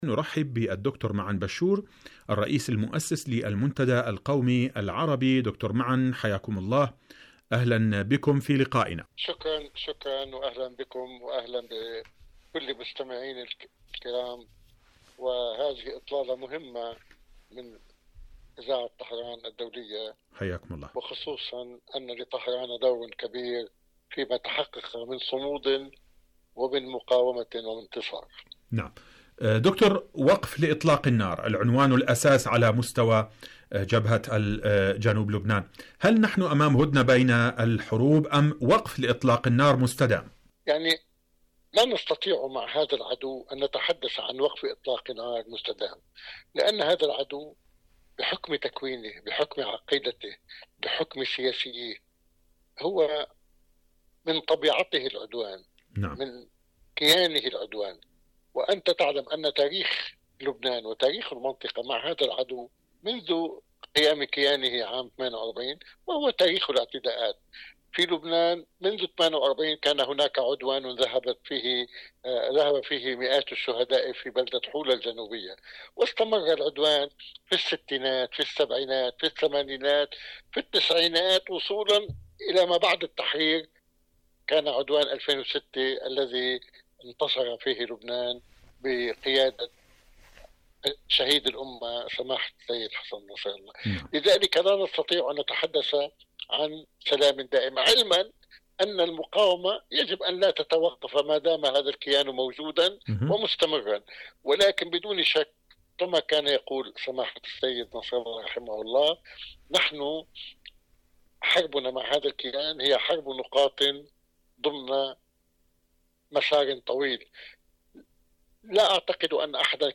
مقابلات إذاعية برنامج فلسطين اليوم